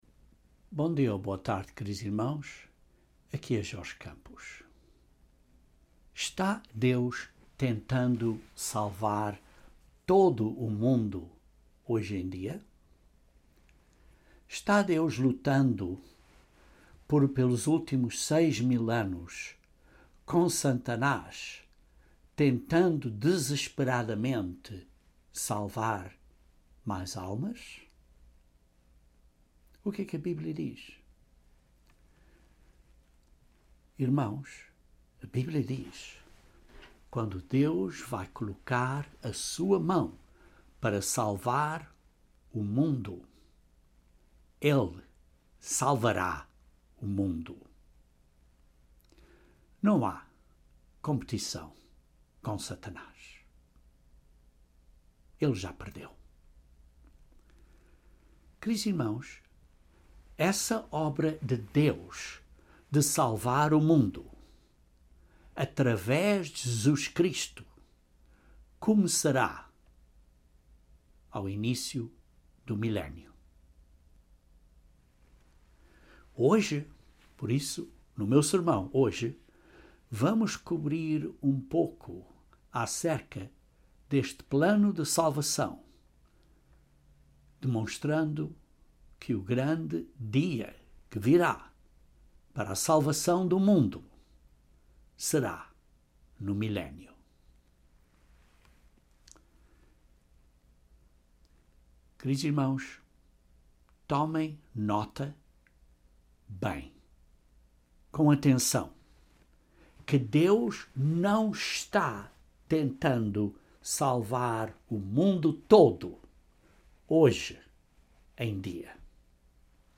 Mas para o resto do mundo o seu dia de Salvação começa no milénio. Este sermão é a primeira parte duma série de sermões acerca do Dia de Salvação.